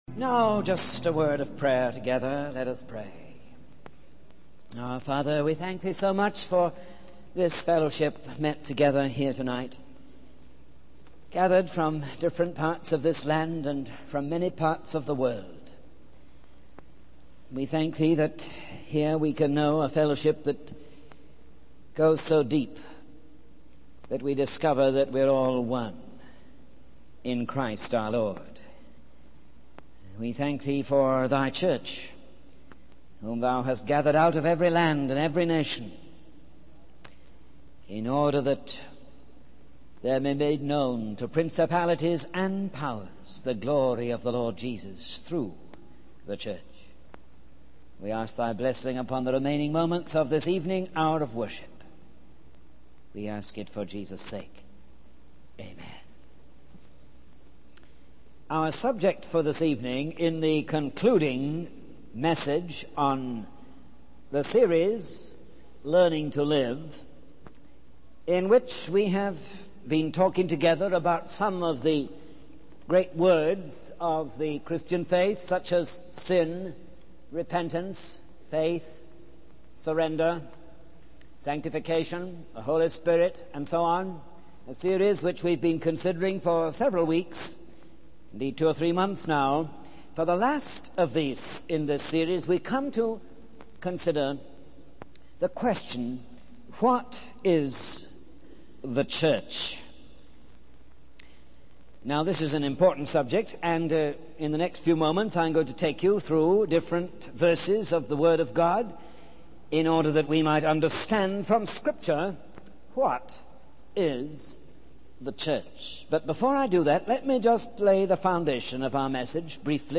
In this sermon, the speaker discusses the importance of the church in the Christian faith. He emphasizes that God reaches people through other people, and the church is the instrument through which believers can win others for Christ.